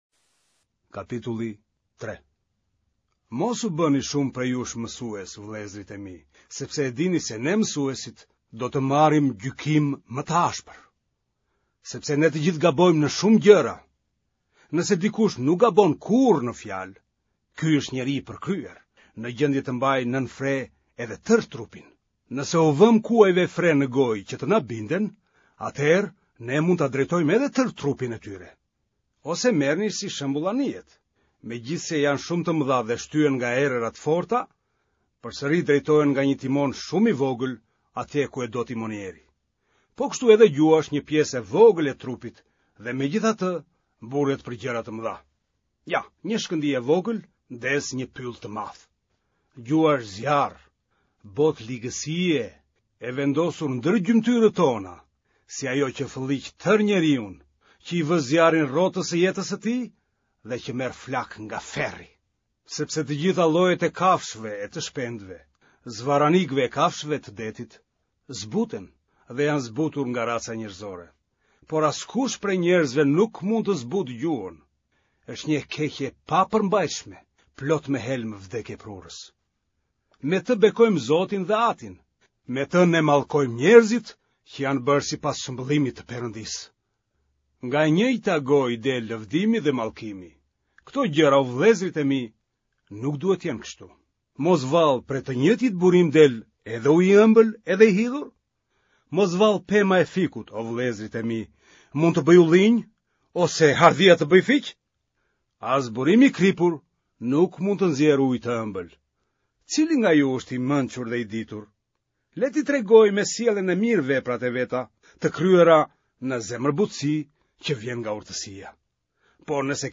Lexoi për ju aktori Timo Flloko